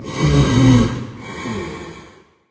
breathe2.ogg